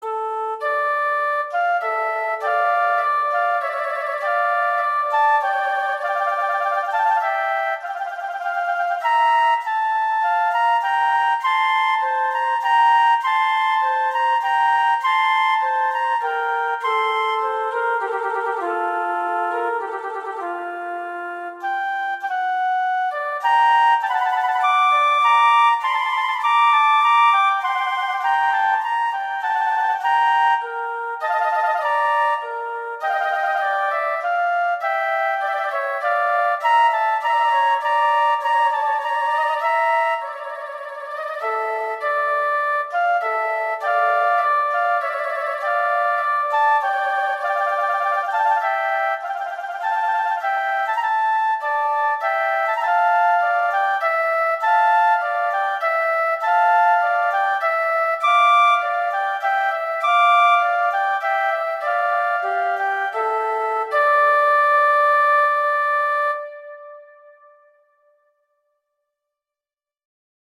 classical, children